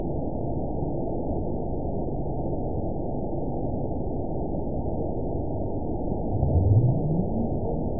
event 922850 date 04/19/25 time 22:54:35 GMT (1 month, 3 weeks ago) score 9.48 location TSS-AB02 detected by nrw target species NRW annotations +NRW Spectrogram: Frequency (kHz) vs. Time (s) audio not available .wav